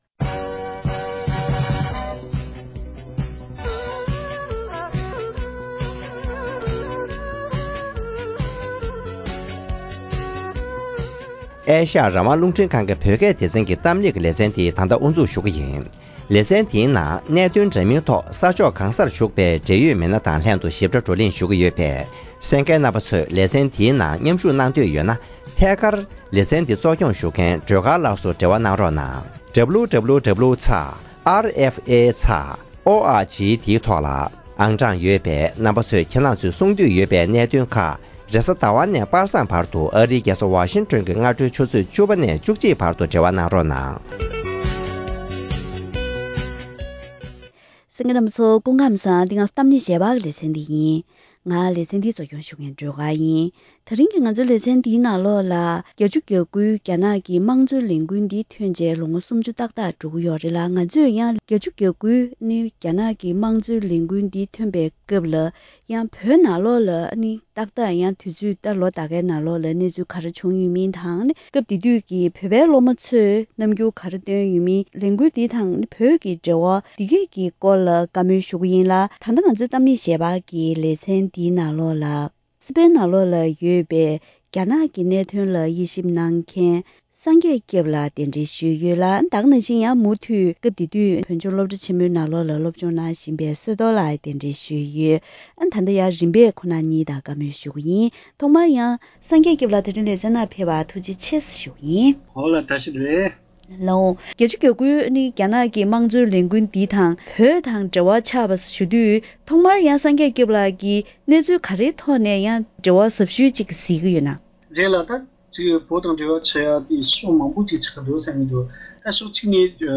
༡༩༨༩ལོར་རྒྱ་ནག་ལ་སློབ་མས་འགོ་ཁྲིད་པའི་དམངས་གཙོའི་ལས་འགུལ་ཐོན་ནས་ལོ་ངོ་སུམ་བཅུ་ཧྲིལ་པོ་འཁོར་བ་དང་བོད་ཀྱི་འབྲེལ་བ་སོགས་ཐད་བགྲོ་གླེང་།